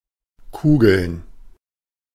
Ääntäminen
Ääntäminen Tuntematon aksentti: IPA: /kuːɡl̩n/ Haettu sana löytyi näillä lähdekielillä: saksa Käännöksiä ei löytynyt valitulle kohdekielelle. Kugeln on sanan Kugel monikko.